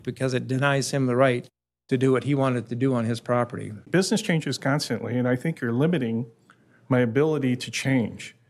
They heard opposition from existing business owners and their lawyers, who argue it could impose limits on their growth, expansion and new construction.